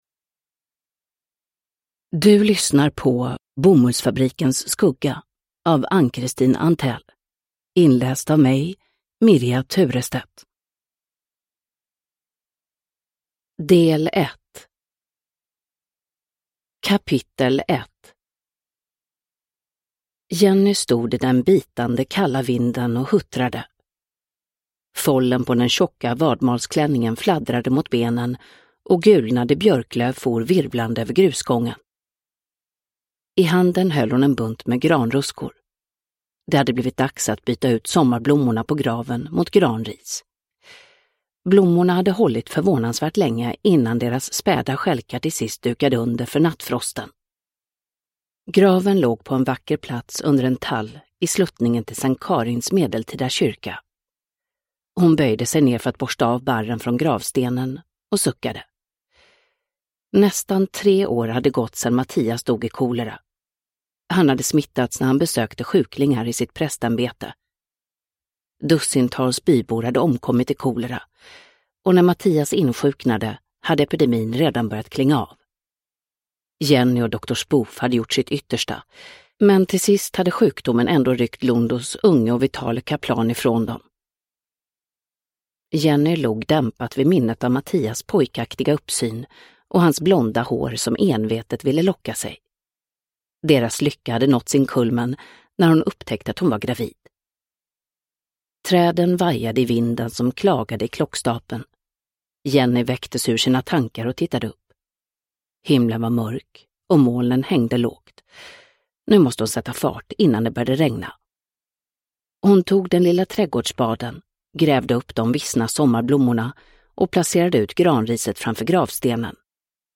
Bomullsfabrikens skugga – Ljudbok – Laddas ner